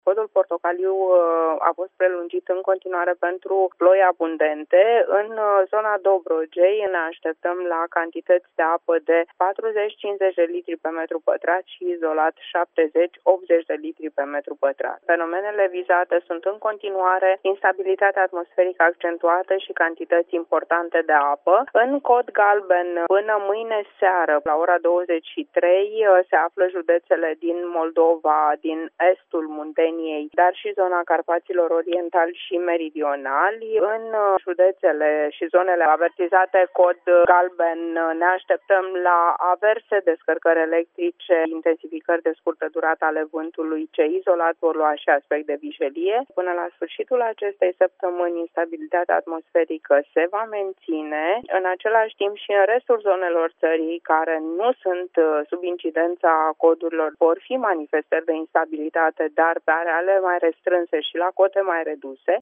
Cu detalii, meteorologul de serviciu